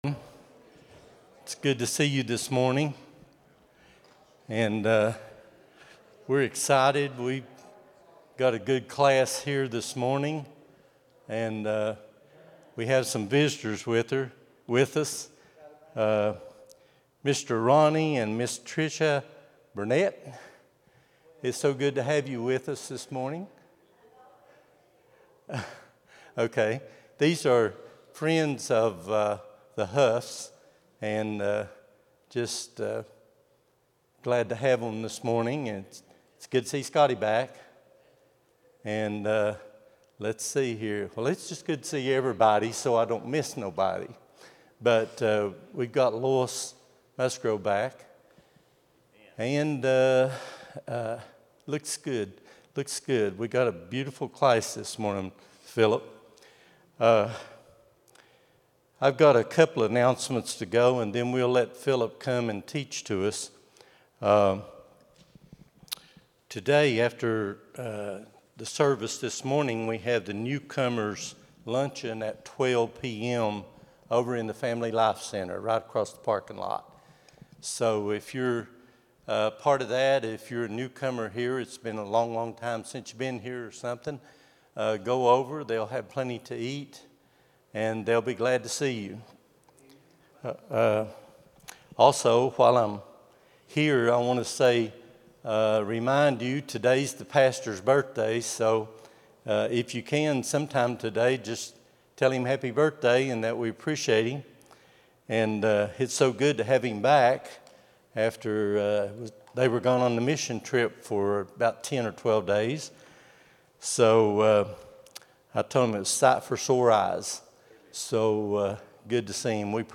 03-15-26 Sunday School | Buffalo Ridge Baptist Church